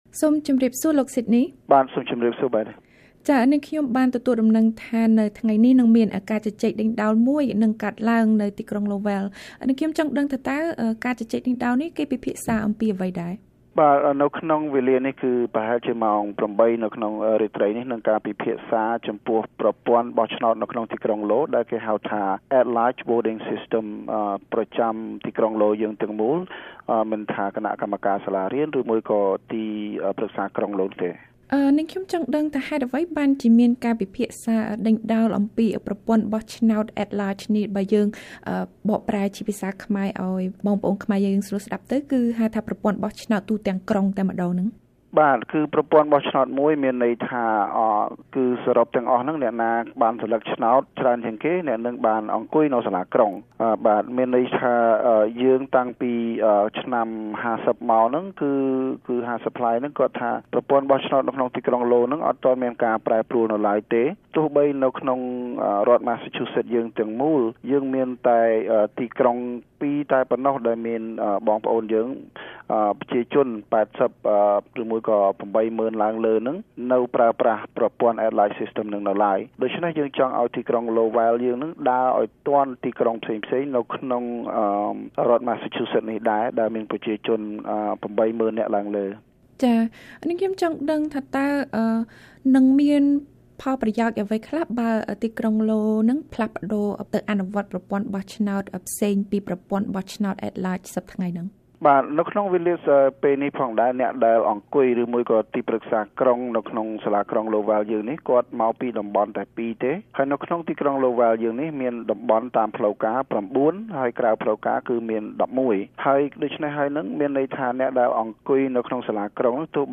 បទសម្ភាសន៍ VOA៖ ការប្តូរប្រព័ន្ធបោះឆ្នោតនៅក្រុងឡូវែលអាចនាំឲ្យមានតំណាងជាជនជាតិភាគតិចនៅសាលាក្រុង